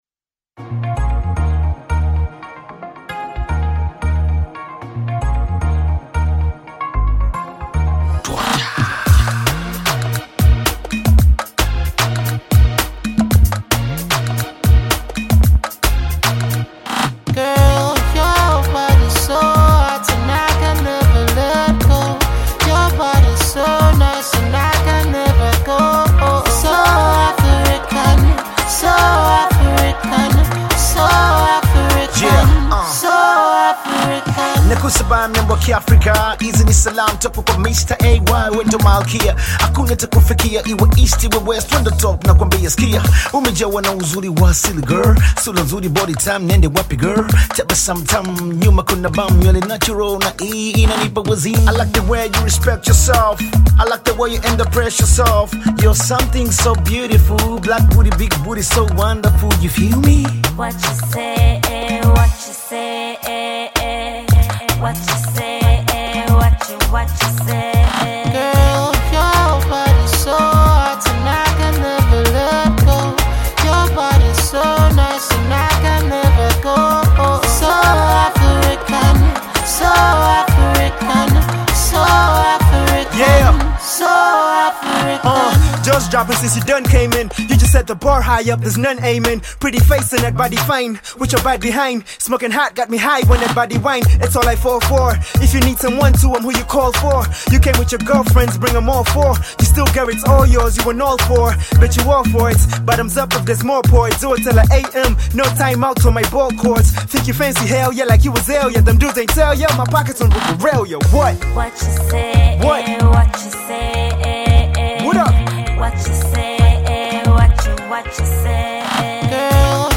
hip-hop/RnB
dance-hall/reggae
with a strong Jamaican patois verse